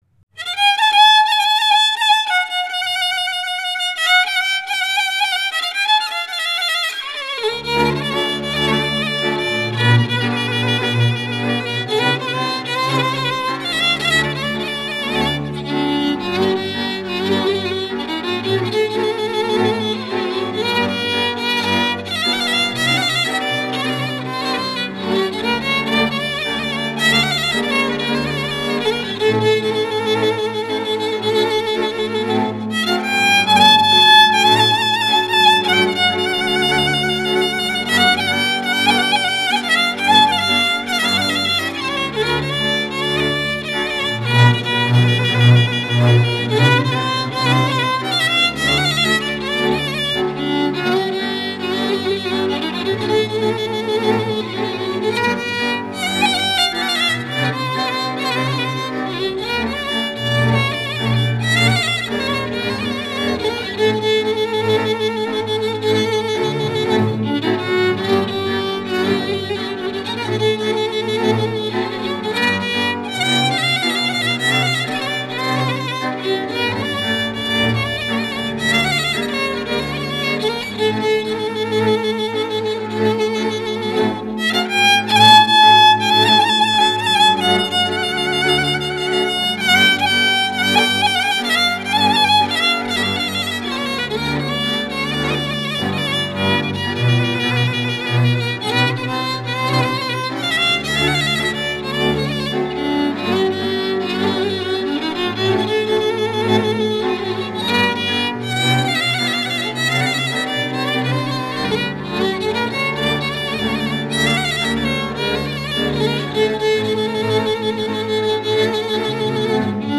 Grupul instrumental din Viișoara